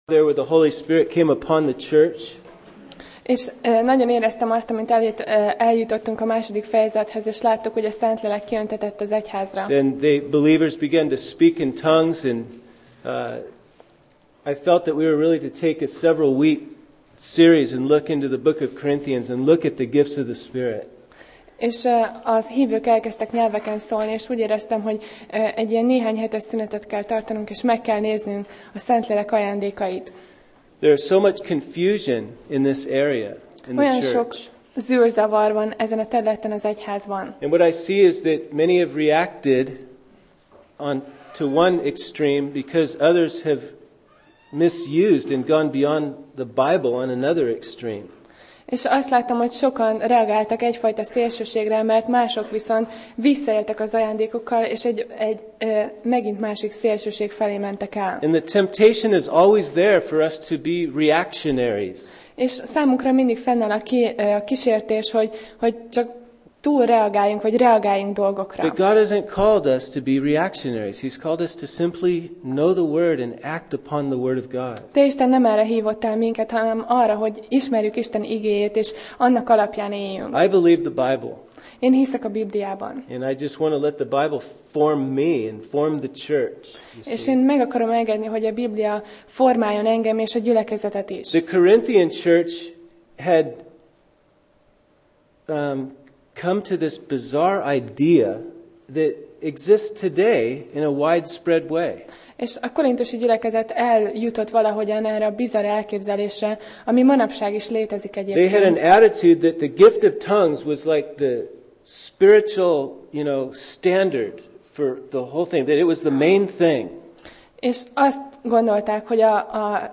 Alkalom: Vasárnap Reggel